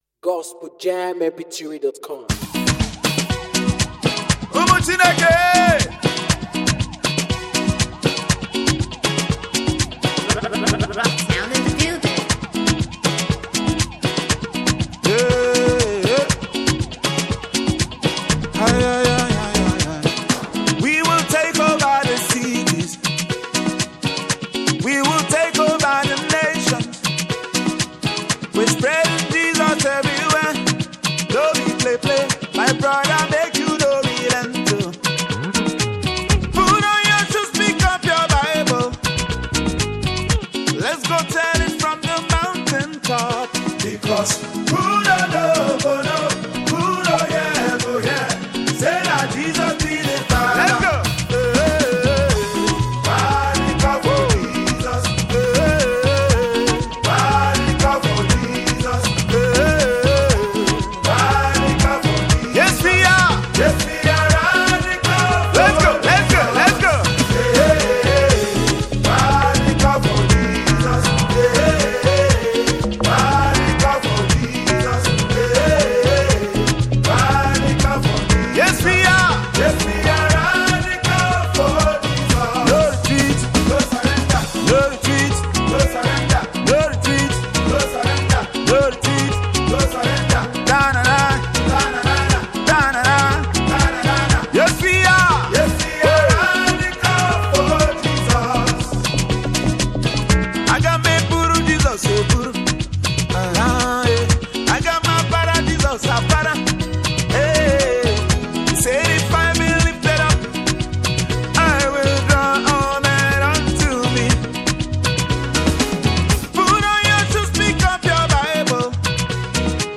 is a passionate and energetic gospel song